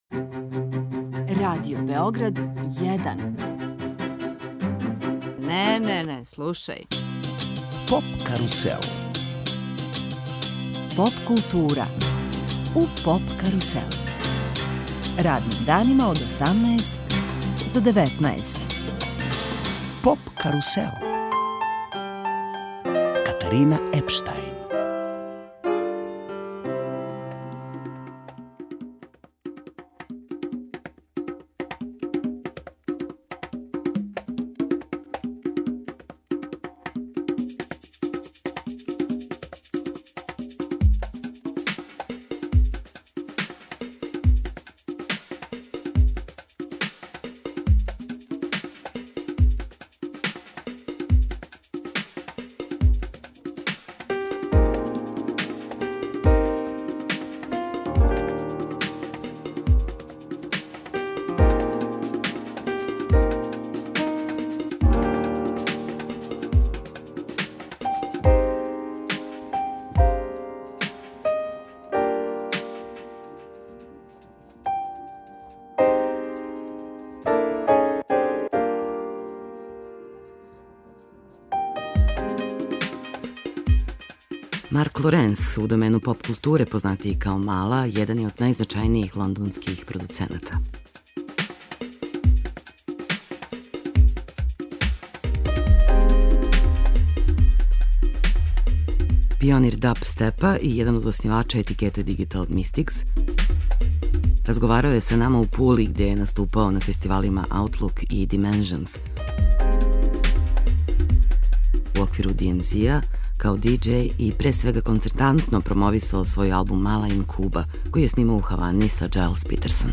Гост емисије је Мала, један од најзначајнијих лондонских продуцената, пионир dubstepa и оснивач етикете DIgital Mystikz. О сопственом процесу стварања, сарадњи са Gilles Peterson-om и раду на албуму MALA IN CUBA (Brownswood, 2012) говорио је за наш програм.